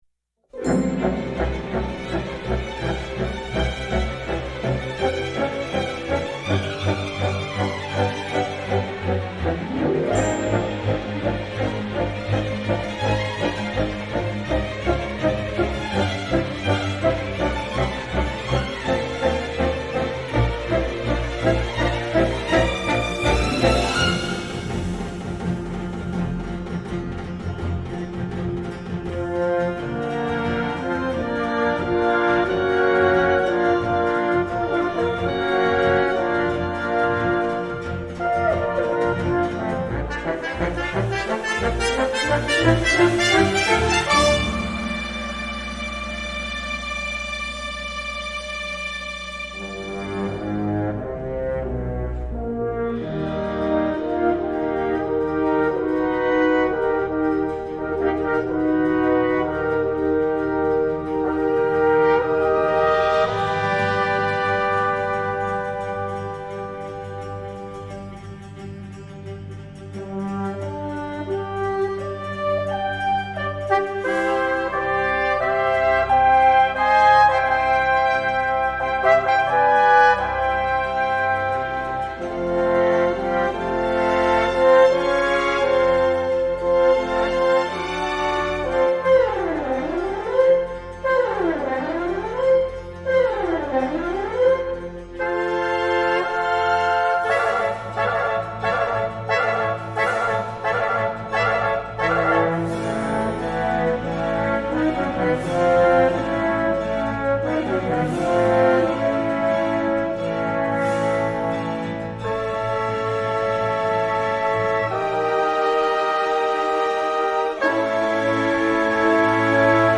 Partition en parfaite synchro avec le film